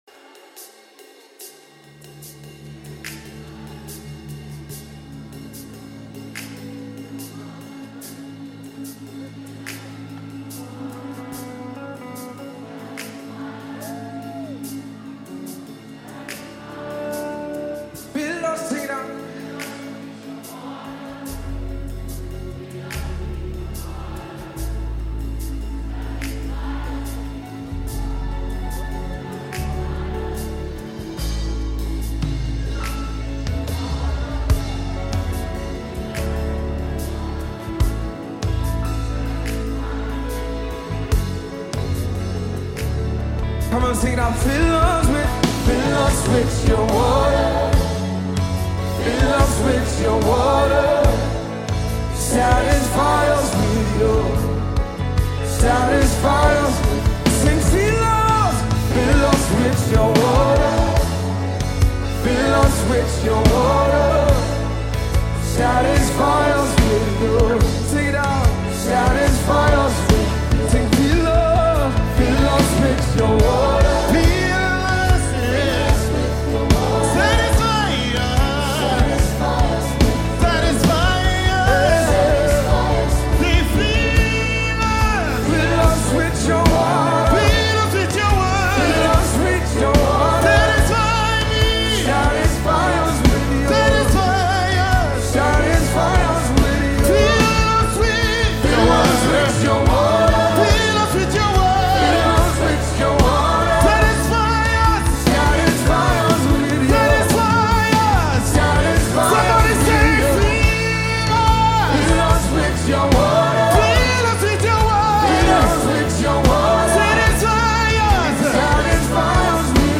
a leading voice in Nigeria’s contemporary gospel scene
alternative, soulful approach